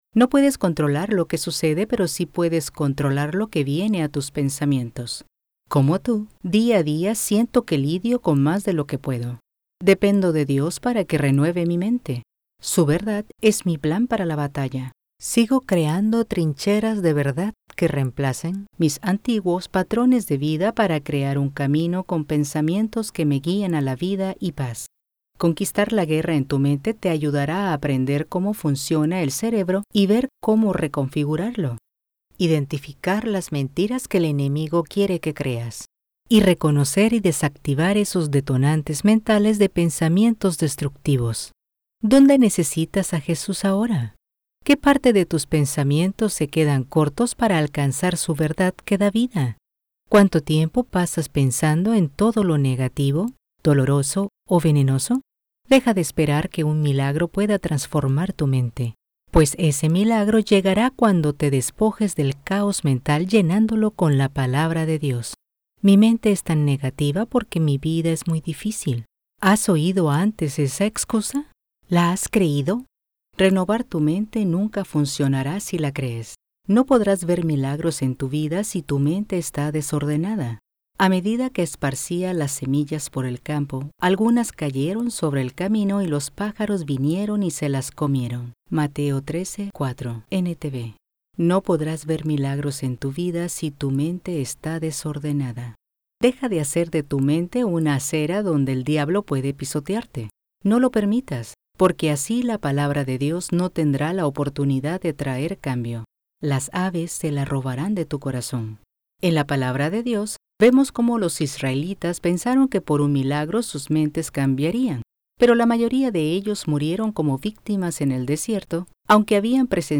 El Sonido de la Mente- Audiobook
Spanish - Neutral
Young Adult
Middle Aged